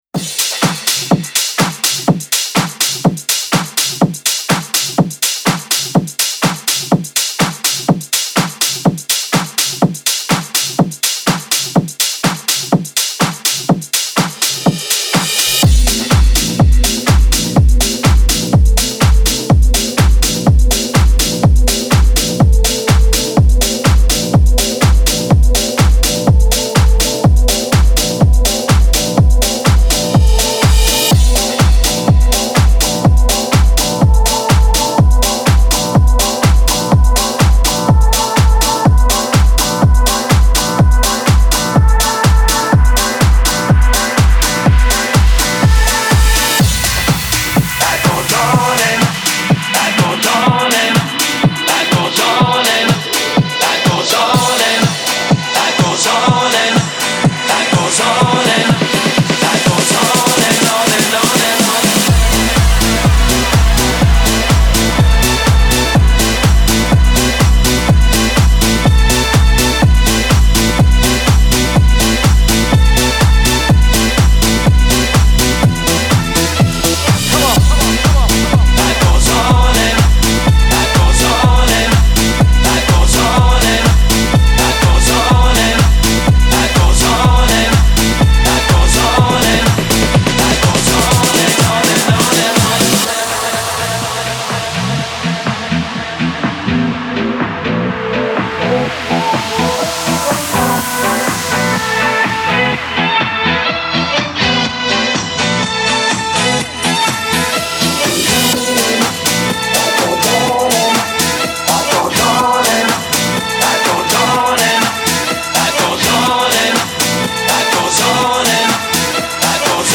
Jackin House Remix